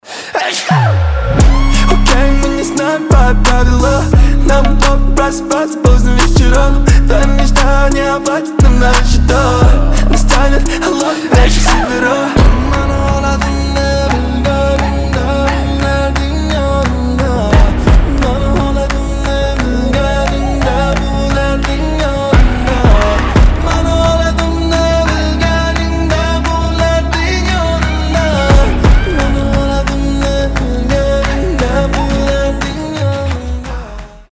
• Качество: 320, Stereo
мужской голос
Хип-хоп
забавные
восточные
этнические